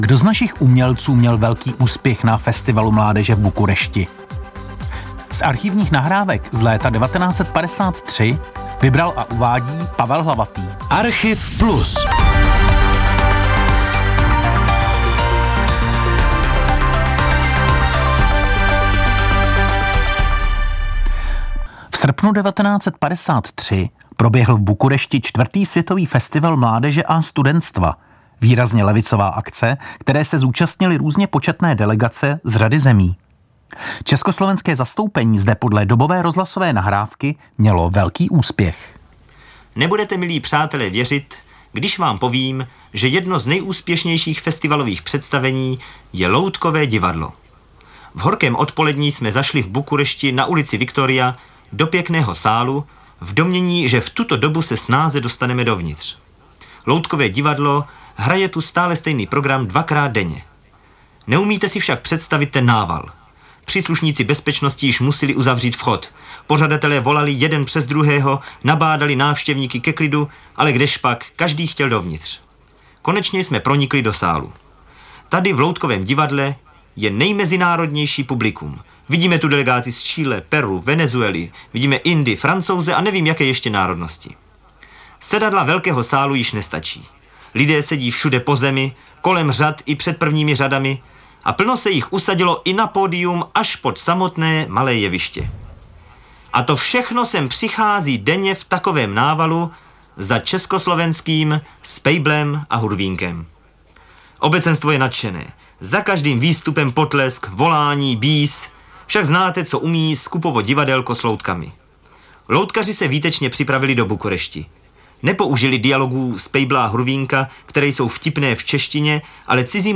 Mezi nimi byl i Československý rozhlas, který přímo v dějišti festivalu natočil reportáž věnující se právě famóznímu úspěchu loutkového divadla.